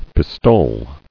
[pis·tole]